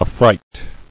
[af·fright]